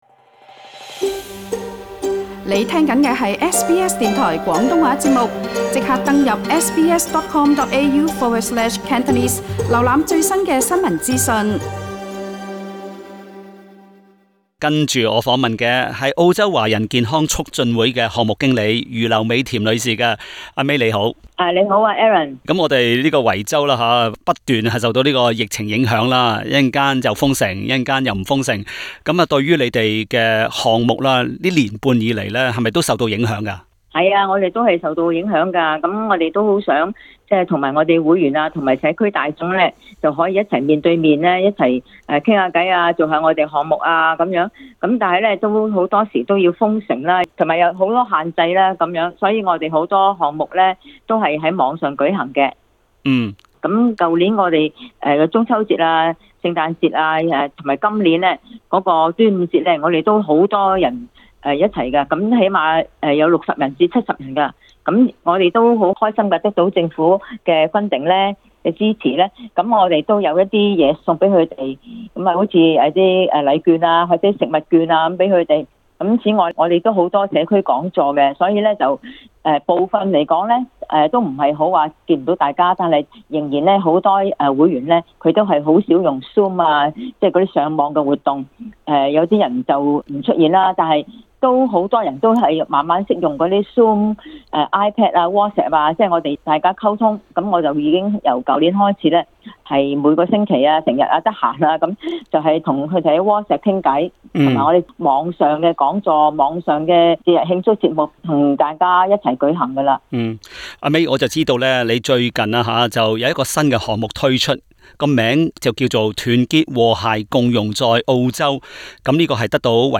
【社区专访】